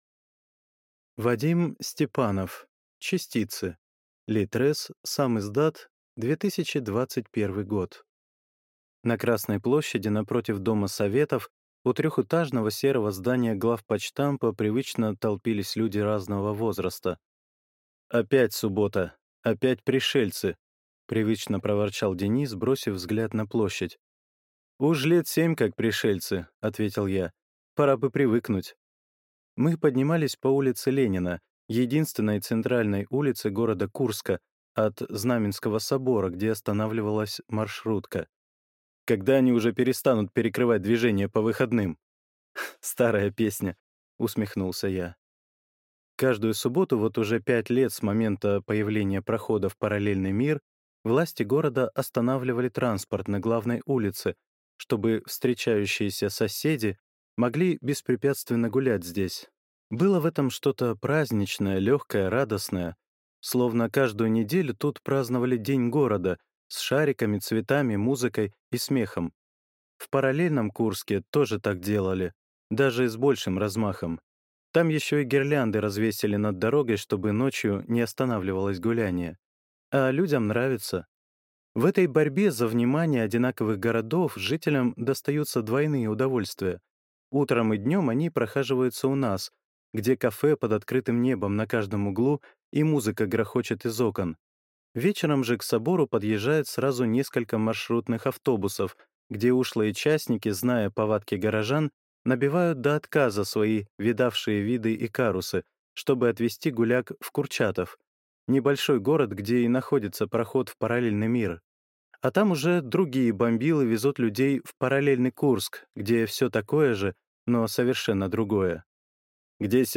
Аудиокнига Частицы | Библиотека аудиокниг